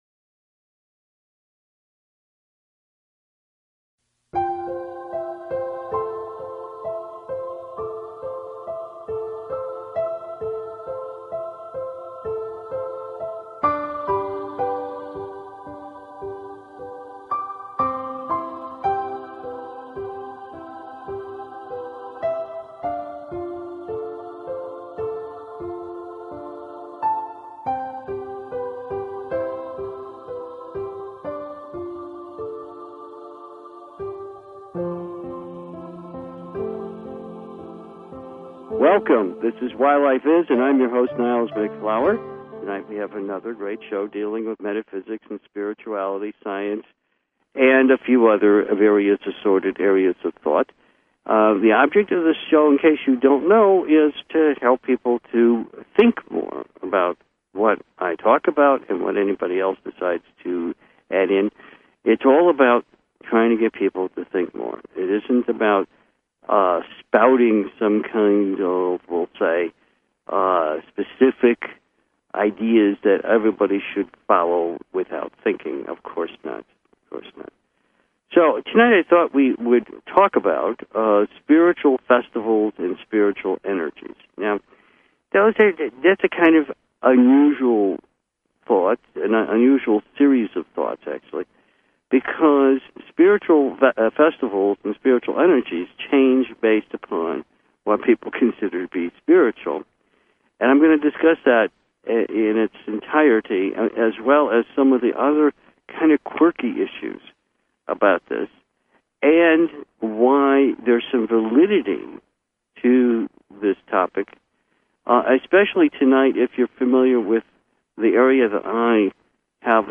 Courtesy of BBS Radio